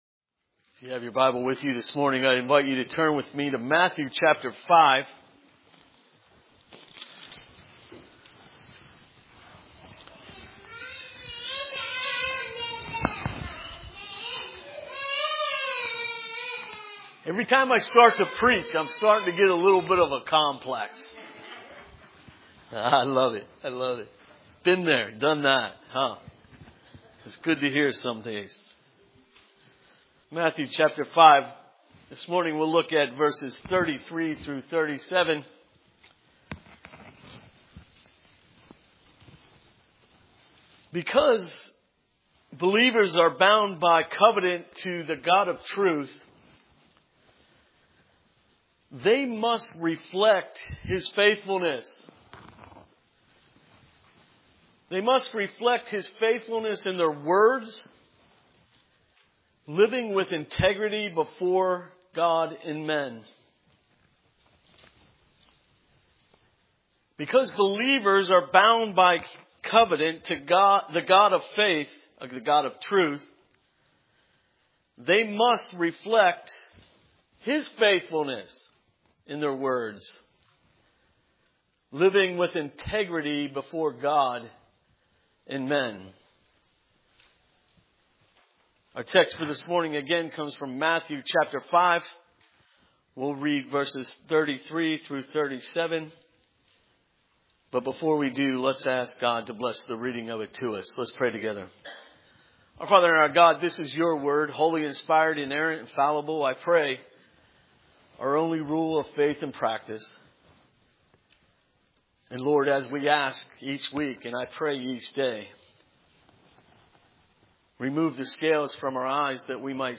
Matthew 5:33-37 Service Type: Sunday Morning Matthew 5:33-37 Words are not cheap.